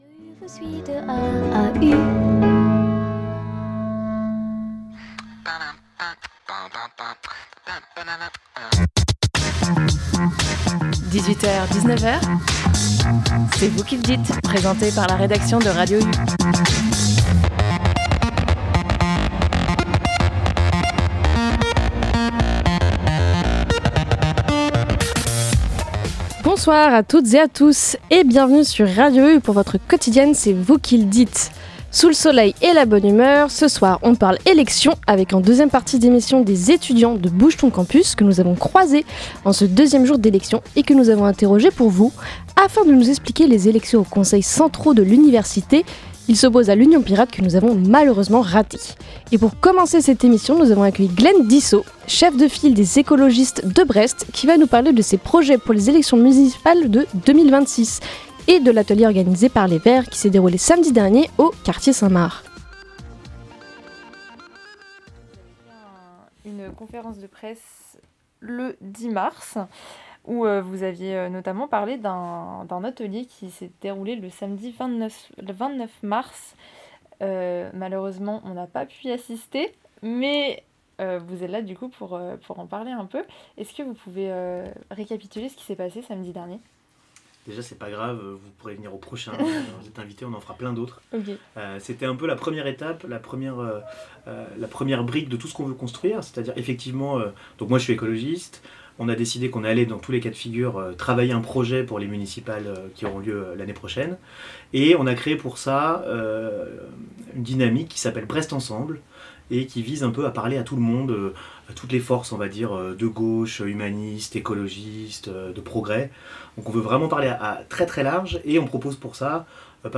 La quotidienne de Radio U, du lundi au jeudi de 18h à 19h, et en direct. Rendez-vous avec la rédaction sur le 101.1, en DAB+ ou en streaming pour tout connaître de l'actualité locale !